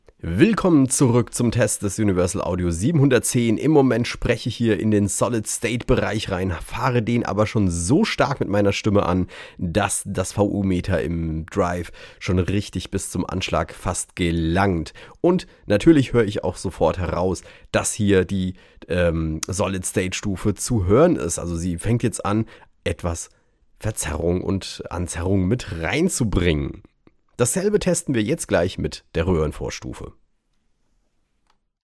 Im Bereich des Solid State klingt es sehr präzise, die Aufnahmen können durch Transparenz bestechen und sind weitestgehend linear.
In den Klangbeispielen findest Du zahlreiche Aufnahmen mit unterschiedlichen Einstellungen für Gain und Mischungsverhältnis der beiden Schaltkreise.